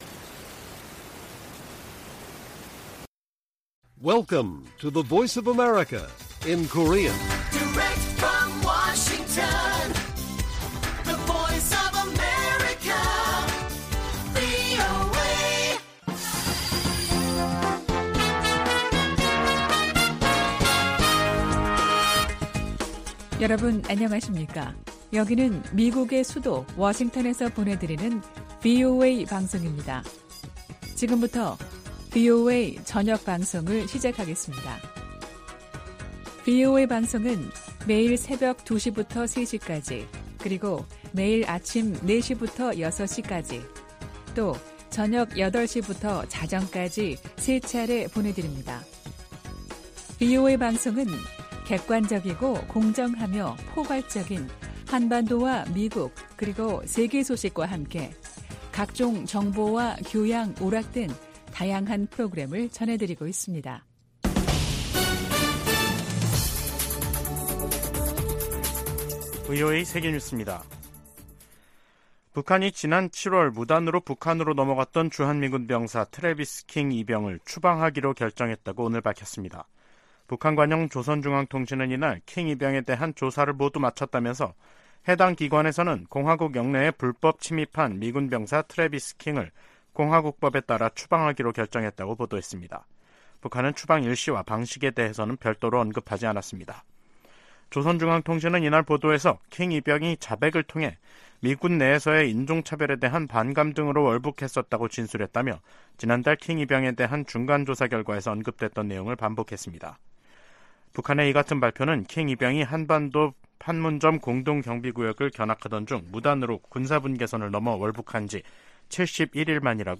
VOA 한국어 간판 뉴스 프로그램 '뉴스 투데이', 2023년 9월 27일 1부 방송입니다. 미 국무부는 미한 연합훈련을 '침략적 성격이 강한 위협'이라고 규정한 북한 유엔대사의 발언에 이 훈련은 관례적이고 방어적인 것이라고 반박했습니다. 한국의 신원식 국방부 장관 후보자는 9.19 남북 군사합의 효력을 빨리 정지하도록 추진하겠다고 밝혔습니다. 미국과 한국, 일본 등은 제 54차 유엔 인권이사회에서 북한의 심각한 인권 유린 실태를 강력하게 규탄했습니다.